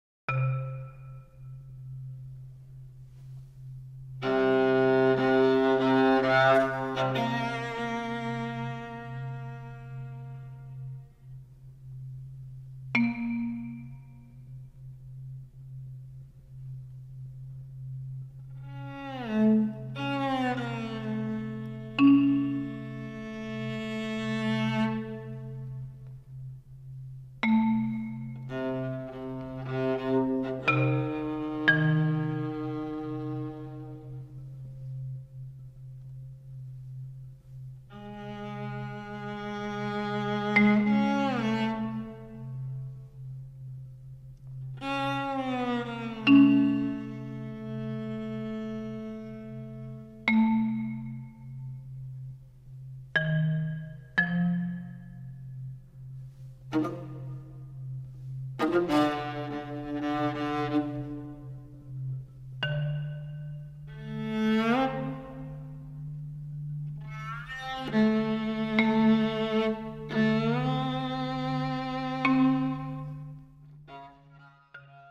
Viola and Marimba
An attractive and virtuosic pairing of instruments.